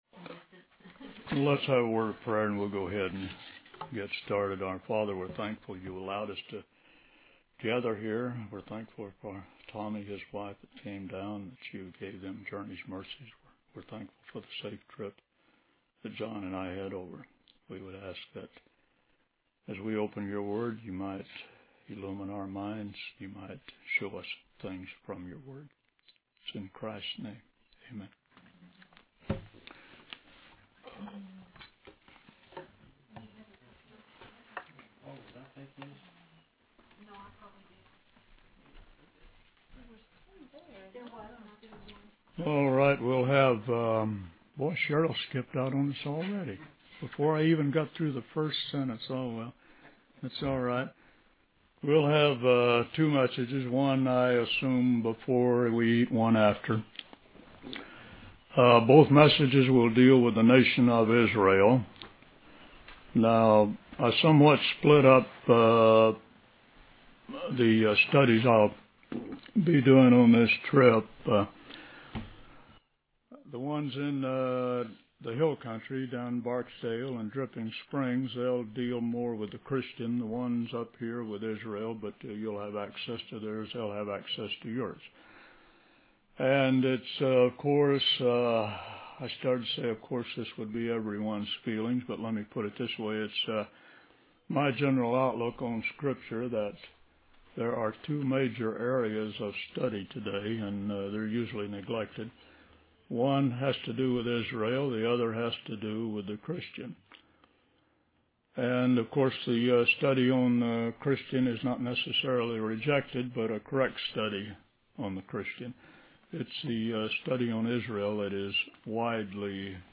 In this sermon, the speaker emphasizes the importance of repetition in teaching and understanding the word of God. He explains that God often states the same matter in different ways throughout the Scripture to help people grasp its meaning.